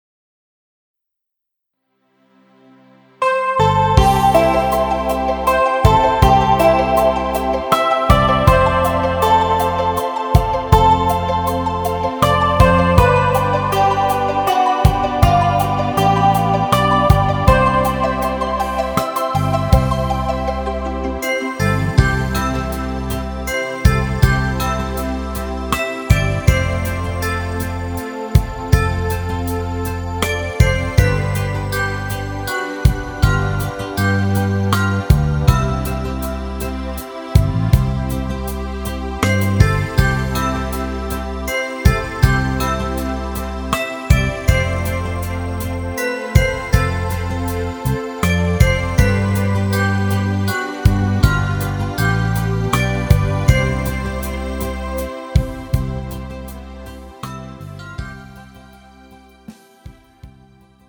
음정 C 원키
장르 가요 구분 Pro MR